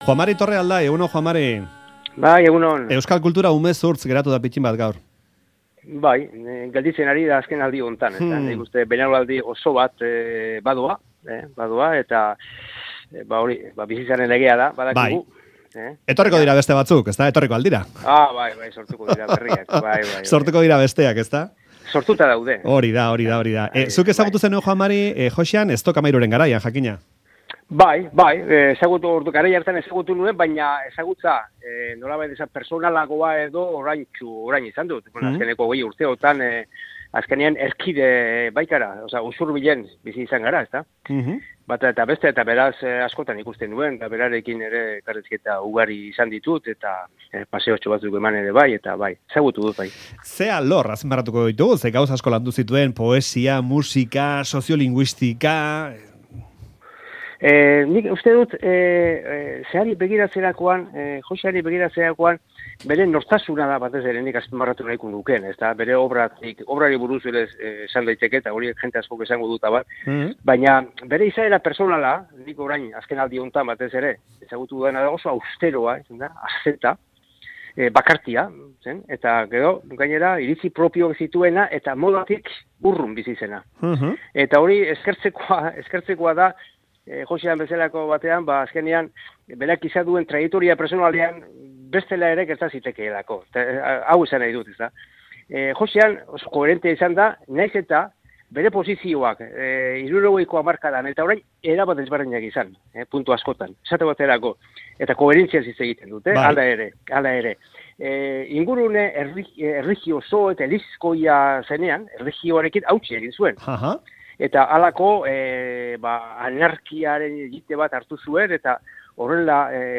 Audioa: Joxan Artze poeta, musikari eta kultur eragile handiaren heriotza jakin orduko, 'Faktoria'n Joan Mari Torrealdairi deitu diogu. idazle usurbildarraren obra baino, izaera azpimarratu nahi izan du.
Pena handiz hitz egin digu, Joxan Artzeren heriotzagatik, "elkar agurtu gabe joan" delako.